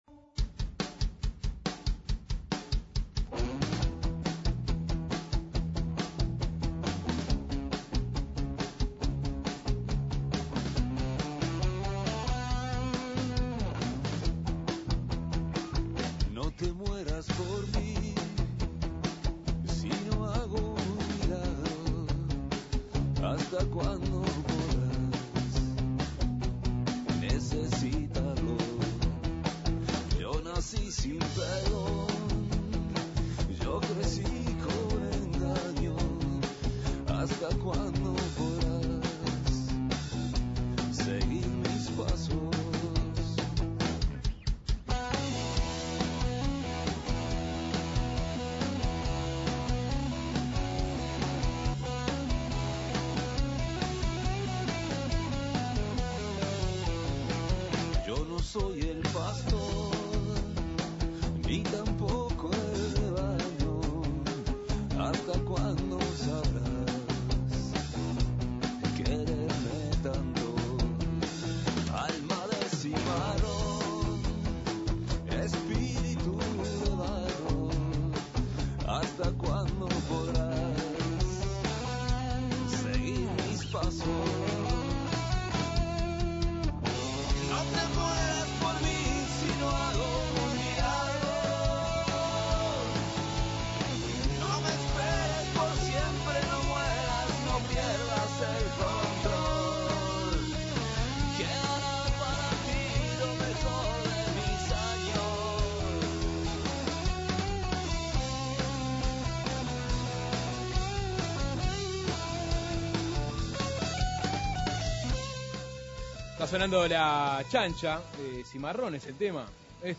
En el marco de "El Día de las Disquerías", Suena Tremendo se mudó hasta 3CDs (en Colonia 1326)